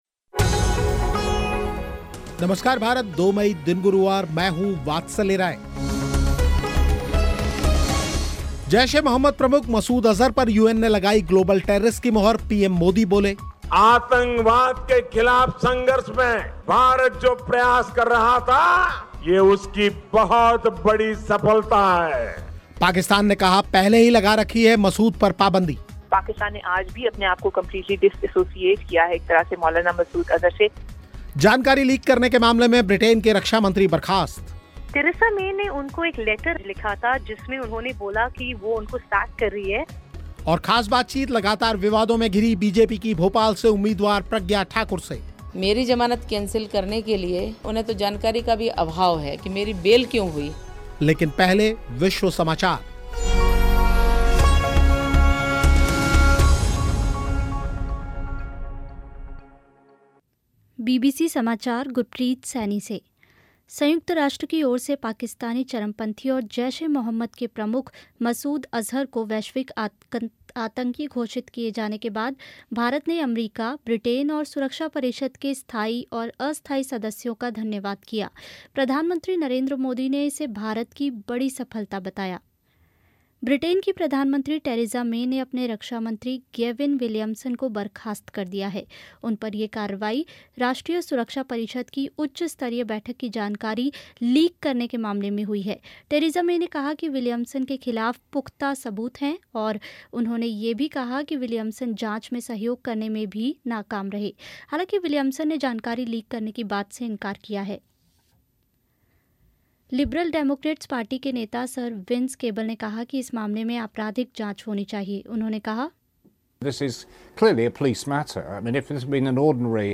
और ख़ास बातचीत, लगातार विवादों में घिरी बीजेपी की भोपाल से उम्मीदवार प्रज्ञा ठाकुर से